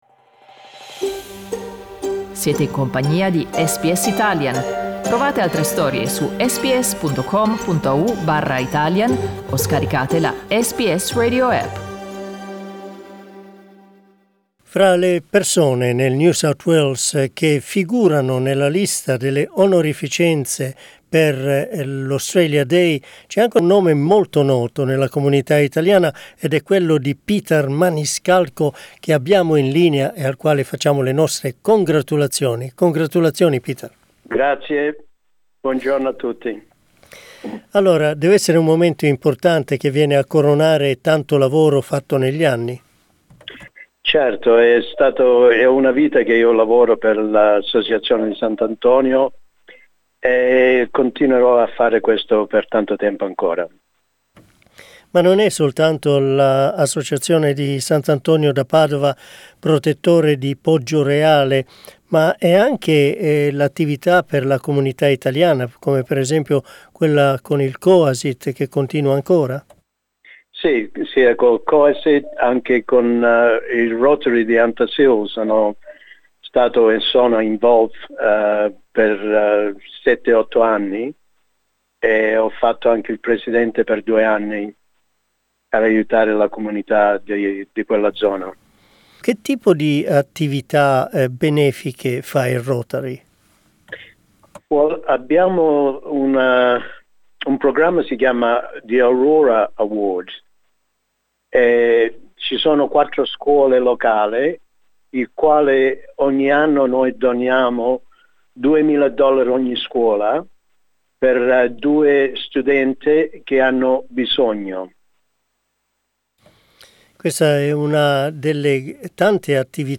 In this interview he talks about his activity and the pride of being recognised by the Australian Government for his community service.